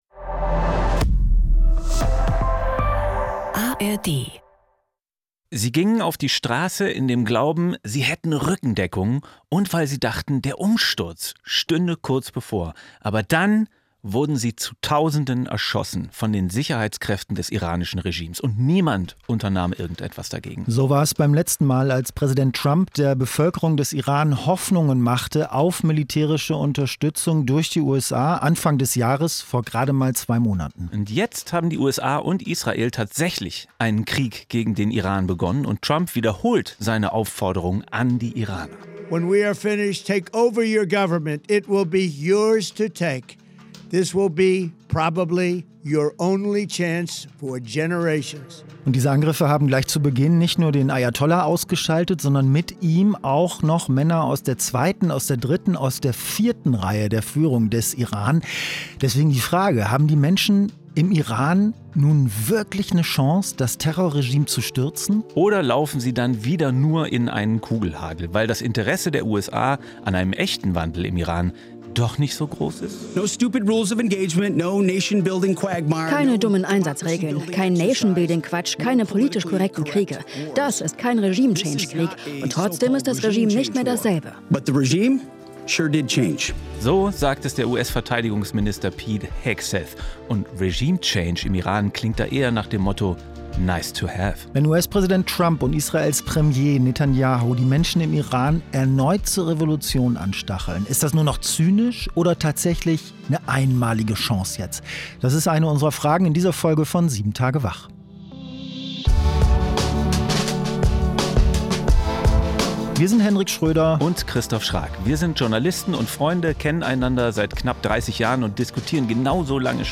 Zwei Freunde, zwei Meinungen, ein News-Podcast